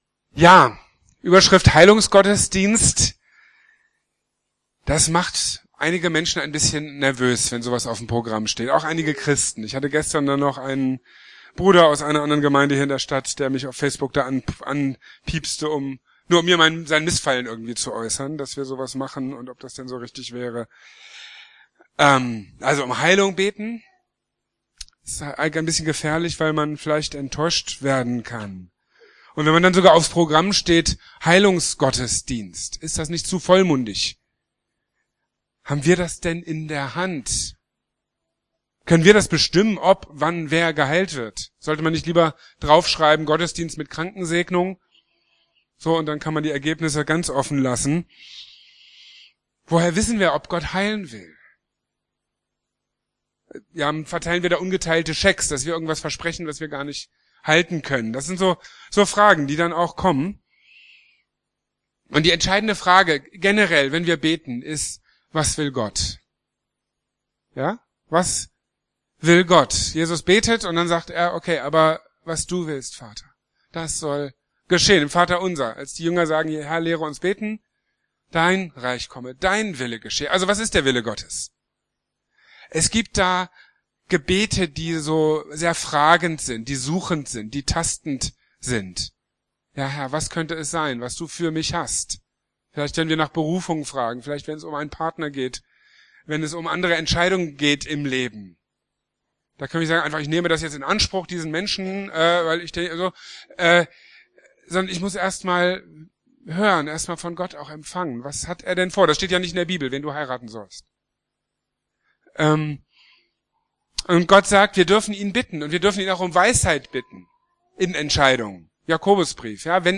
In seiner Predigt im Heilungsgottesdienst am 2.